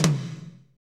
TOM F S H17L.wav